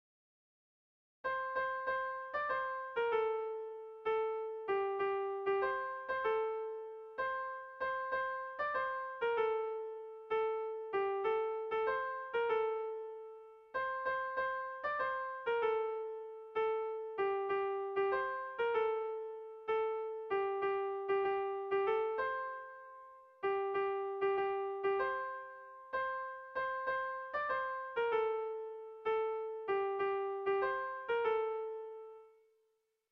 Kontakizunezkoa
Hamarreko txikia (hg) / Bost puntuko txikia (ip)
AAABA